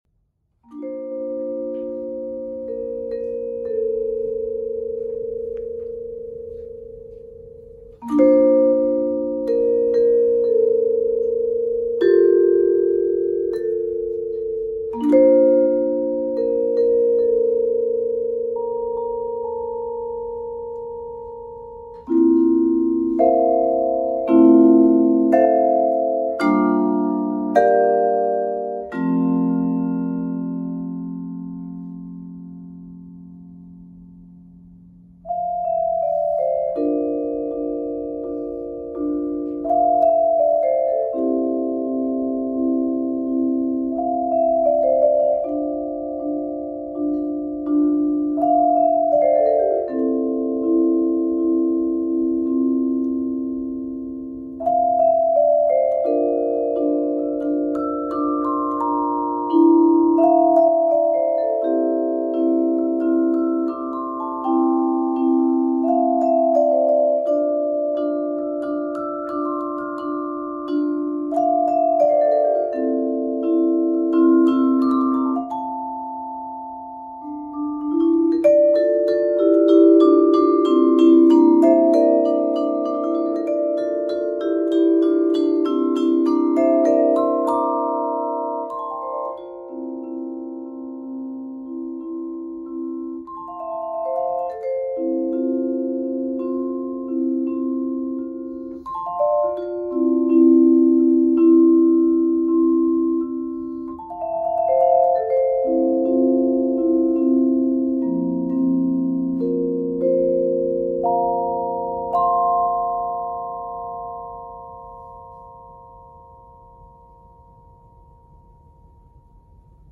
Genre: Solo Vibraphone
Vibraphone (3-octave)